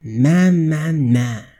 Play, download and share MahMahMah original sound button!!!!
priesttalking.mp3